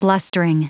Prononciation du mot blustering en anglais (fichier audio)
Prononciation du mot : blustering